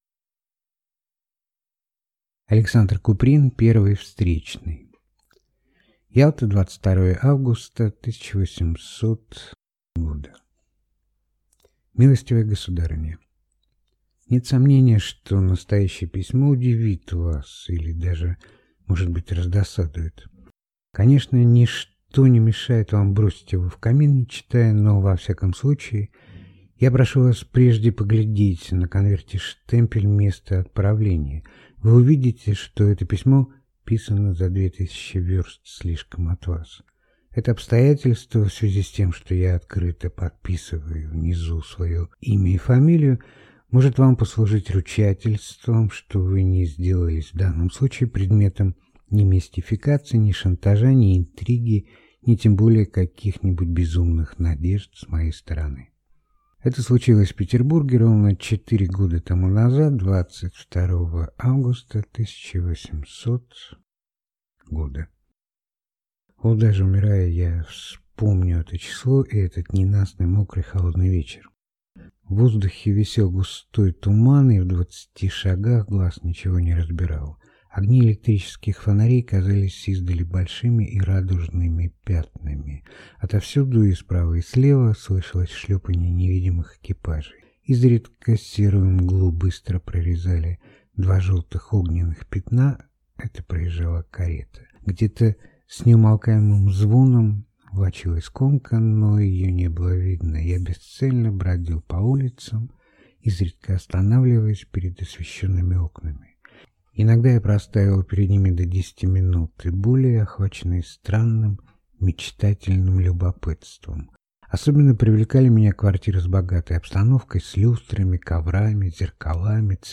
Аудиокнига Первый встречный | Библиотека аудиокниг